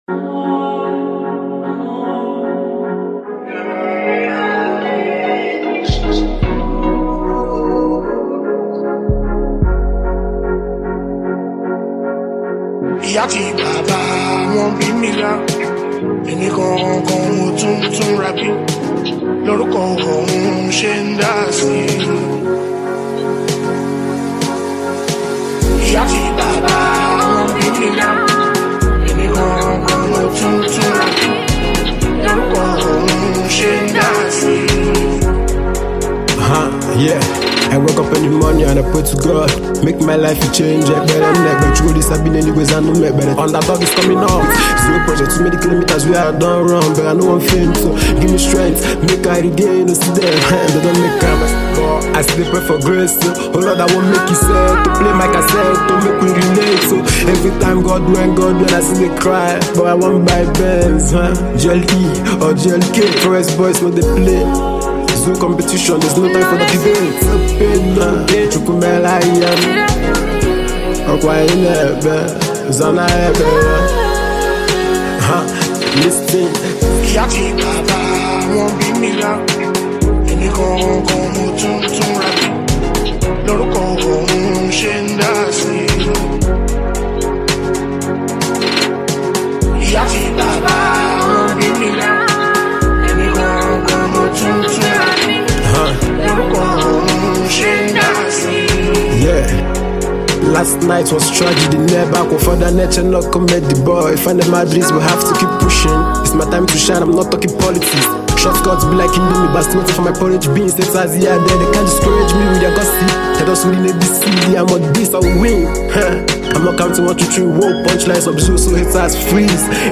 street-hop